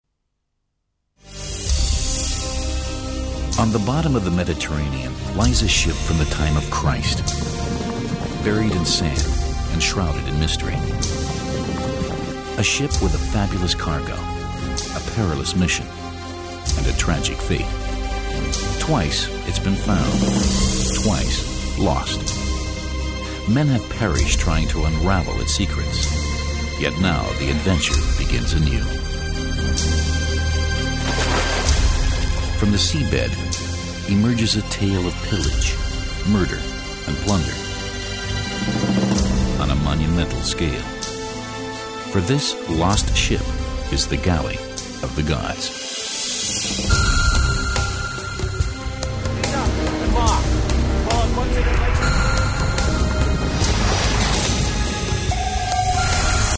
Profi-Sprecher englisch (us).
Sprechprobe: Sonstiges (Muttersprache):
english voice over artist.